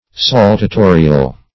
Meaning of saltatorial. saltatorial synonyms, pronunciation, spelling and more from Free Dictionary.
Search Result for " saltatorial" : The Collaborative International Dictionary of English v.0.48: Saltatorial \Sal`ta*to"ri*al\, a. 1.